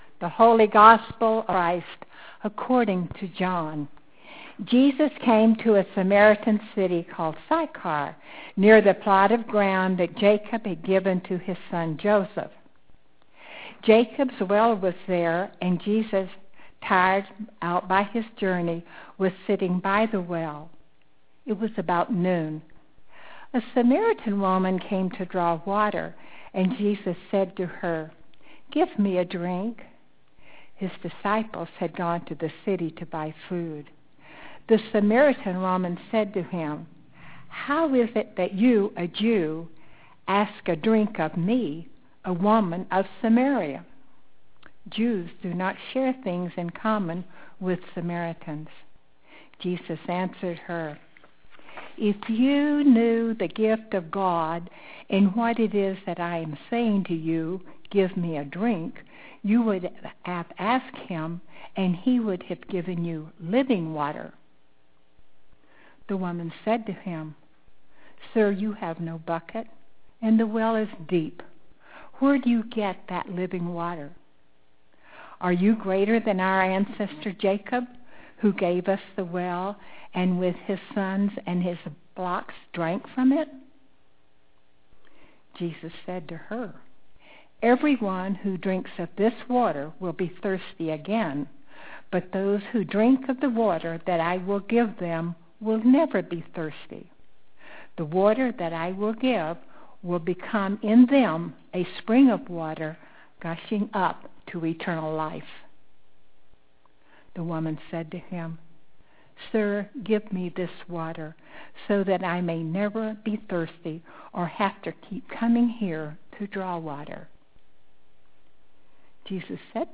Sermons — Page 9 — St. Peter's Episcopal Church – Carson City